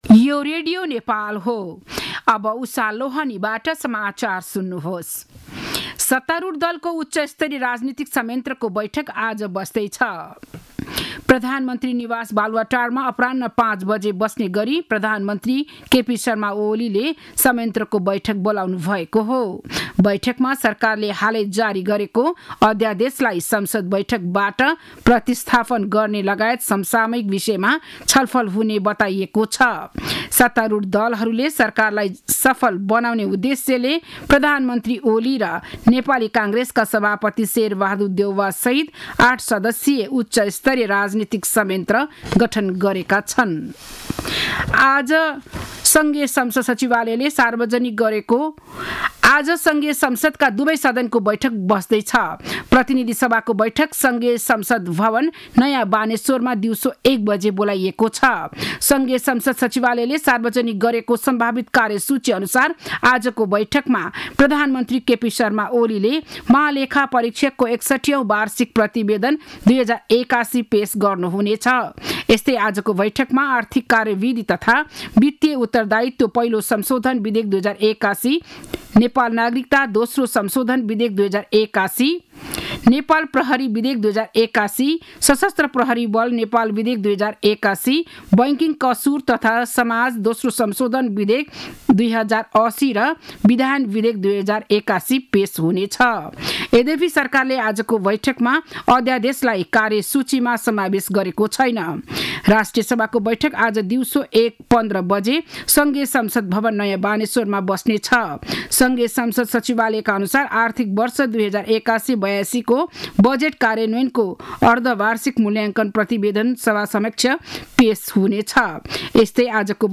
बिहान ११ बजेको नेपाली समाचार : २८ माघ , २०८१
11-am-news-1-3.mp3